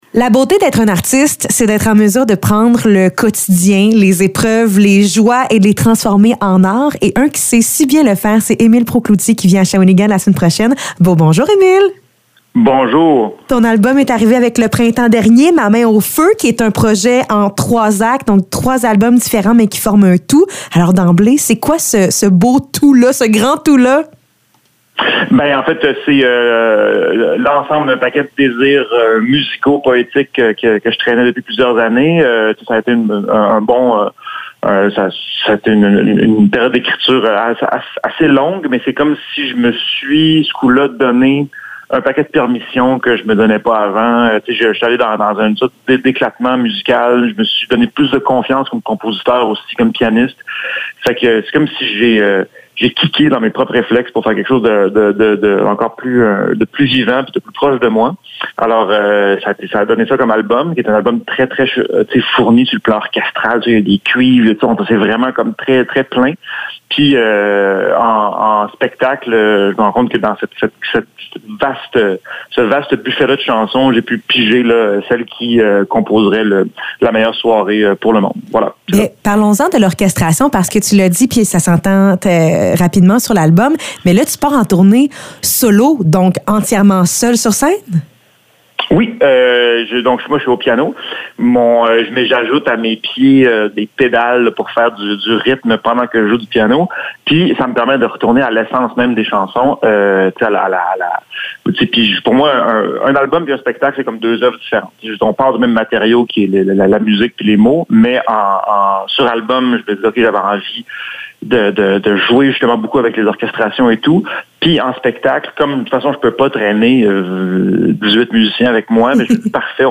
Entrevue avec Émile Proulx-Cloutier